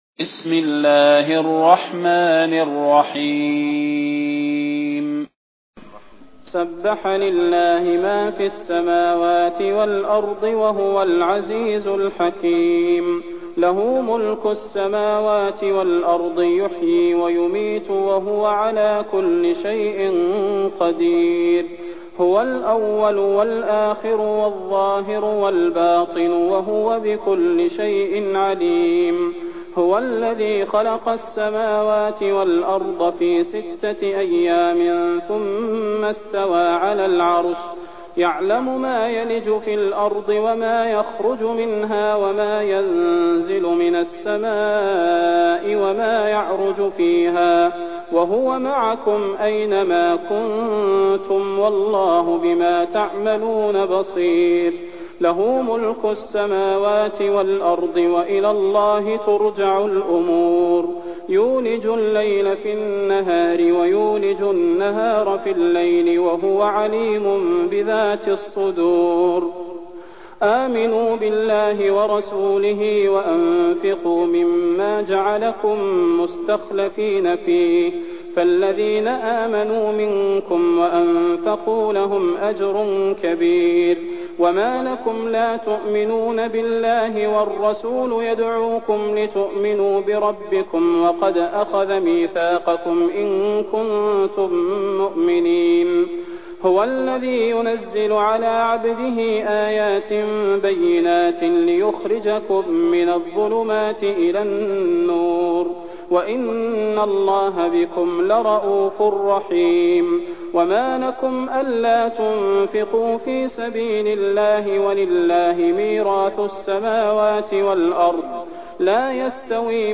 المكان: المسجد النبوي الشيخ: فضيلة الشيخ د. صلاح بن محمد البدير فضيلة الشيخ د. صلاح بن محمد البدير الحديد